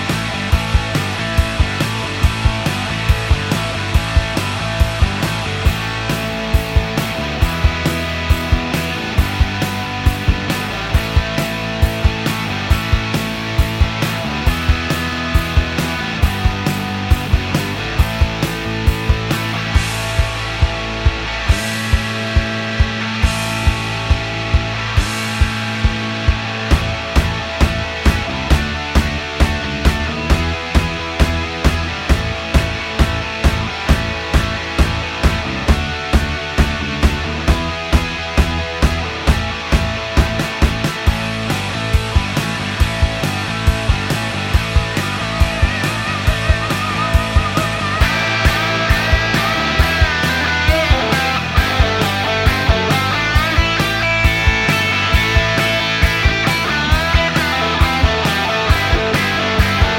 no Backing Vocals Punk 3:44 Buy £1.50